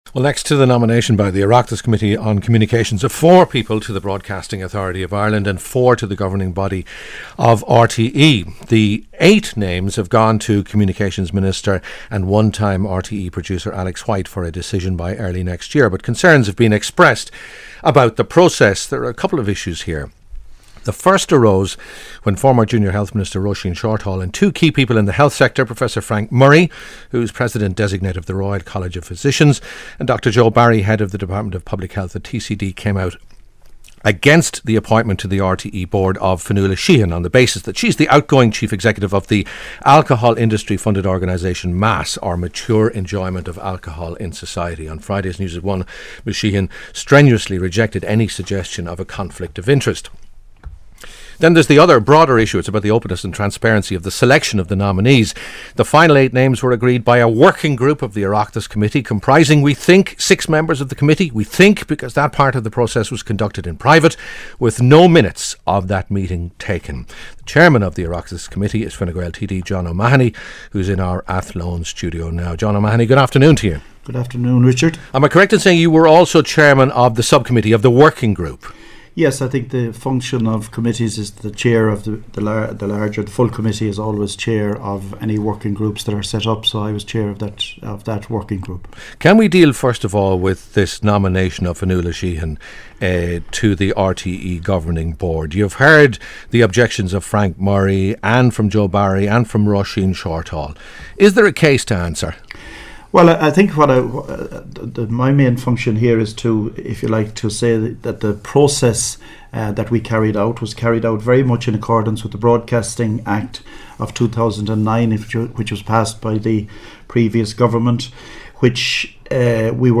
Radio 1's This Week programme discussion with the Chairperson of the Oireachtas Communications Committee regarding the RTÉ and BAI Board appointments.